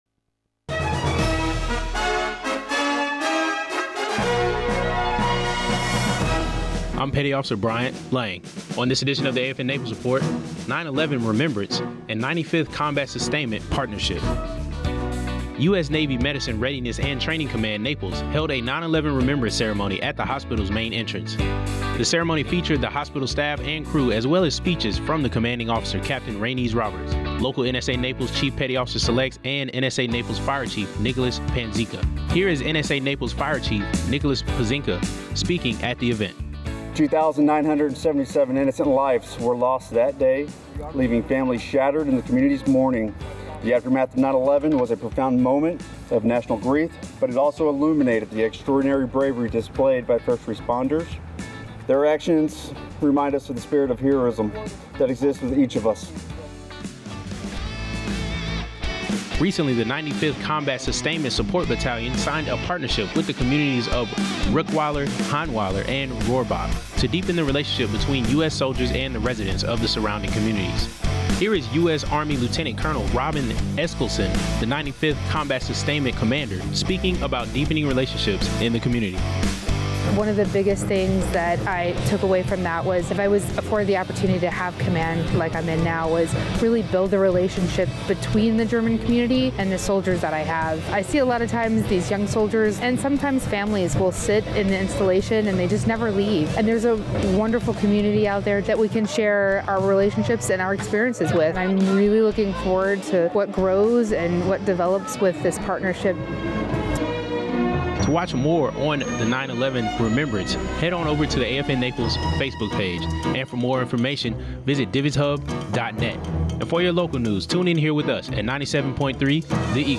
Regional news highlighting the NMRTC Naples 9/11 ceremony and a partnership between the 95th Support command and surrounding communities.